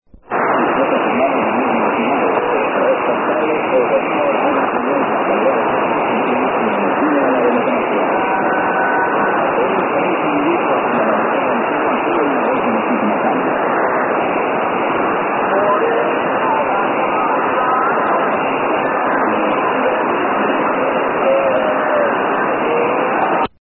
La Voz de los Cuchumatanes heard on their second harmonic of 2100 kHz in Davenport, Iowa, USA on 29 December 1992 at 1102 UTC: